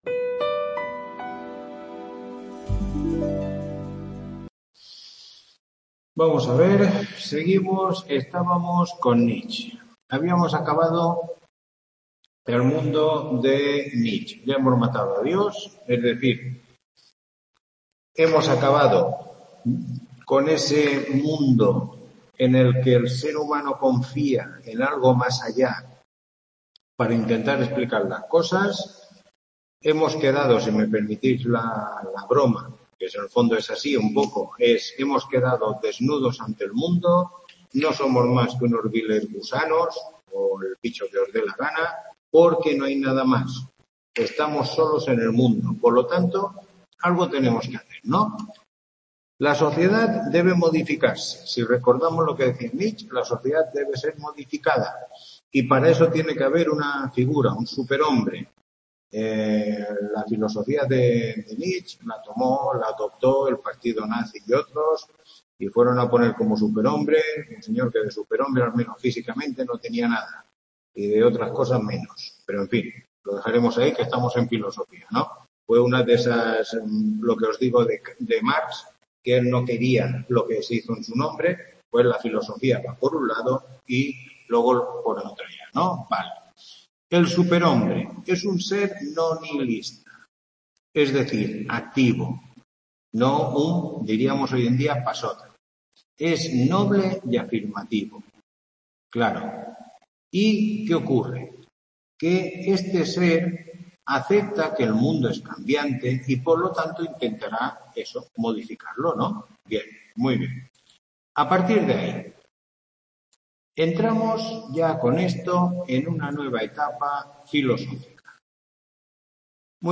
Tutoría 7